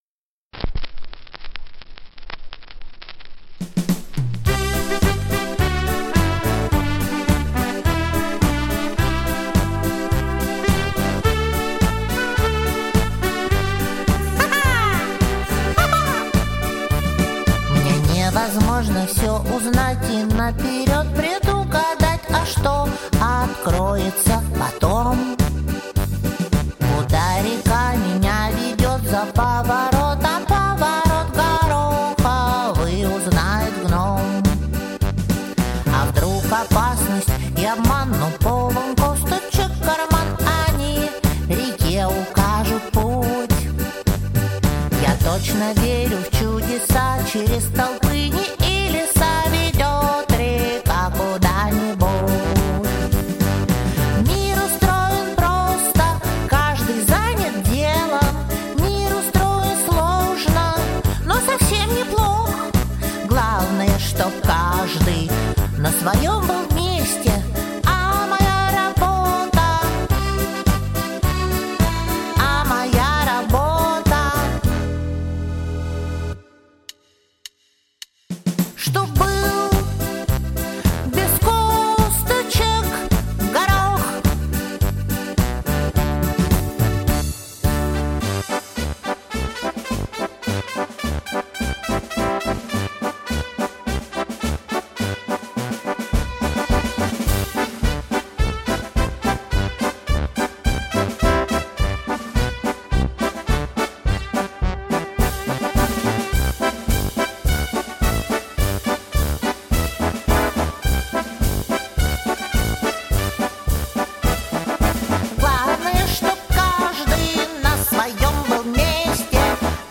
Аудиокнига Приключение Горохового Гномика | Библиотека аудиокниг